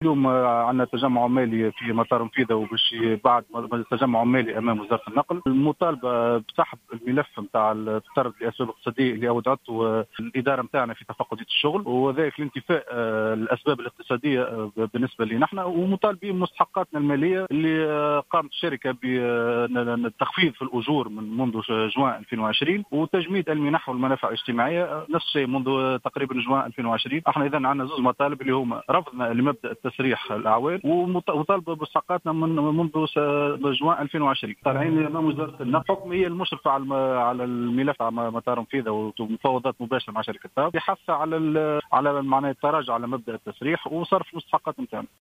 في اتصال هاتفي بالجوهرة أف-أم